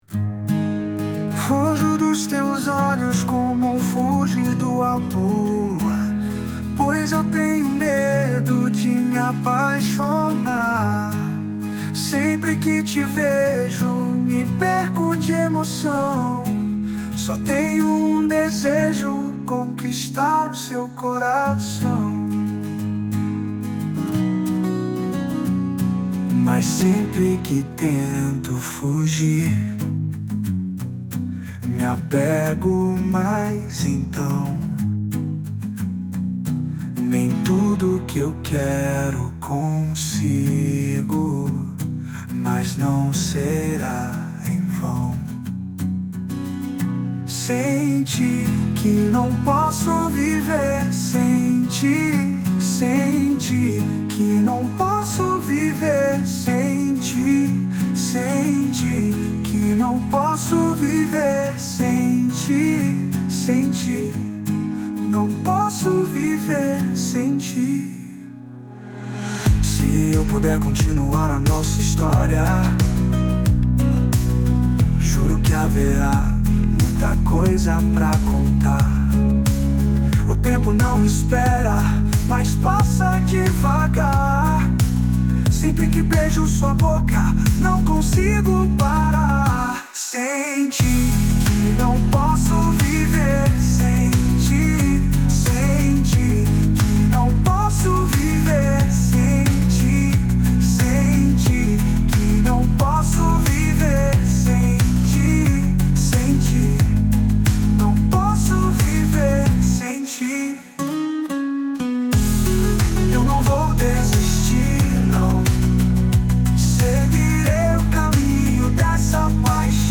EstiloExperimental